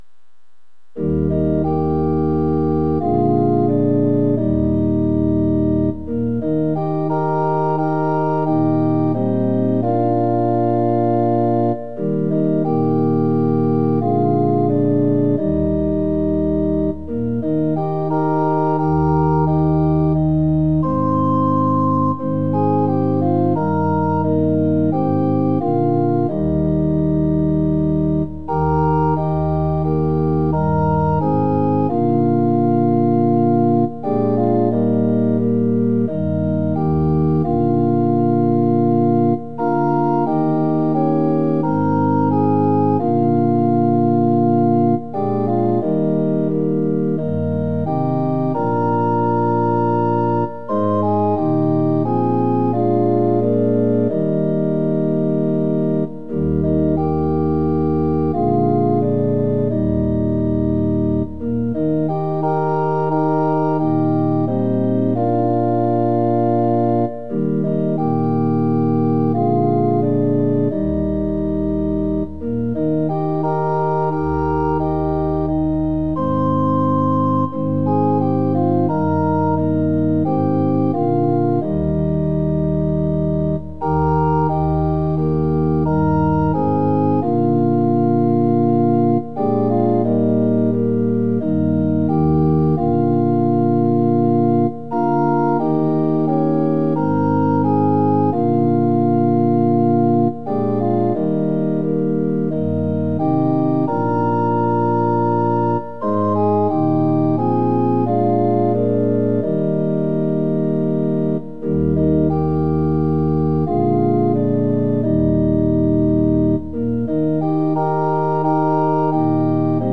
◆　４分の４拍子：　４拍目から始まります。